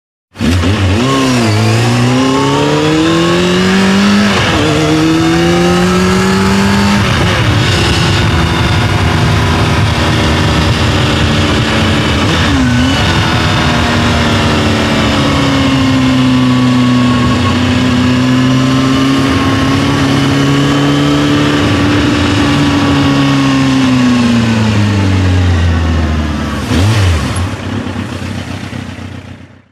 دانلود آهنگ موتور 1 از افکت صوتی حمل و نقل
جلوه های صوتی
دانلود صدای موتور 1 از ساعد نیوز با لینک مستقیم و کیفیت بالا